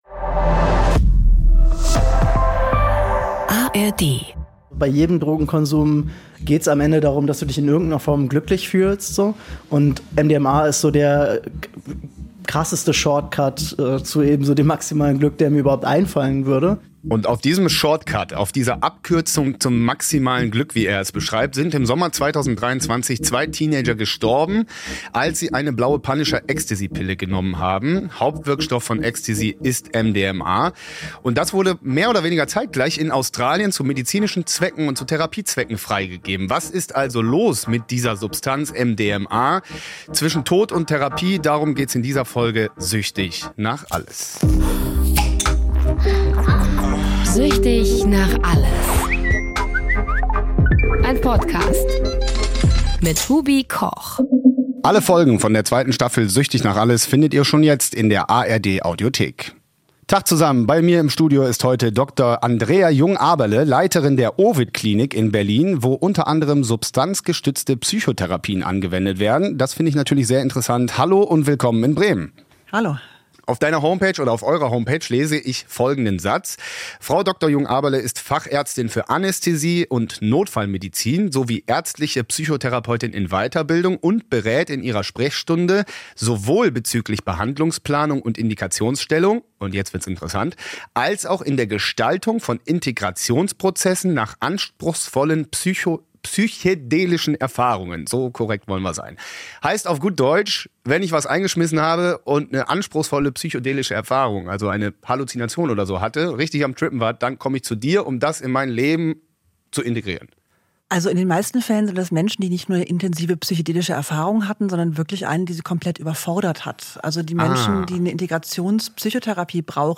Die Aufnahmen für diesen Podcast entstanden bereits im Sommer 2023.